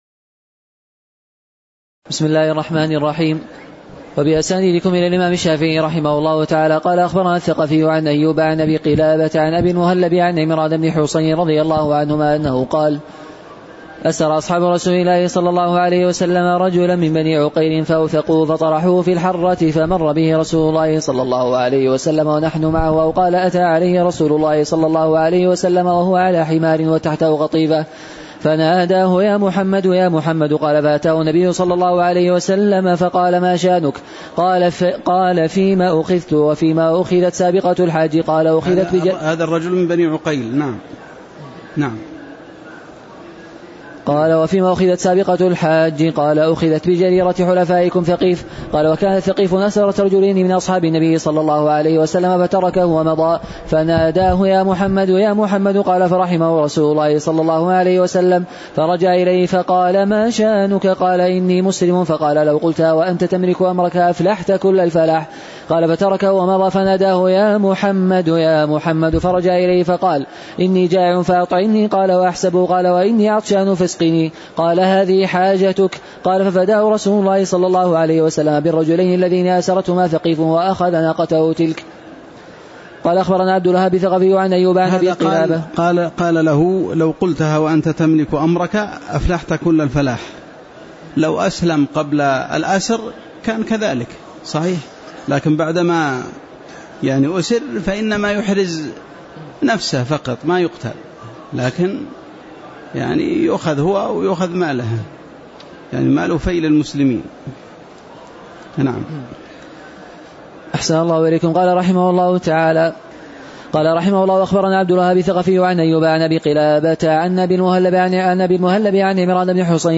تاريخ النشر ١٥ جمادى الآخرة ١٤٣٨ هـ المكان: المسجد النبوي الشيخ